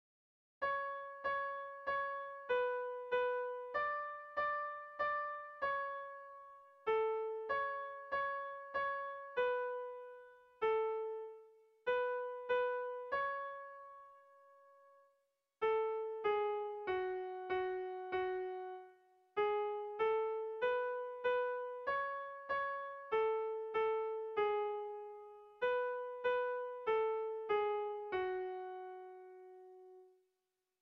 Air de bertsos - Voir fiche   Pour savoir plus sur cette section
Lauko handia (hg) / Bi puntuko handia (ip)
AB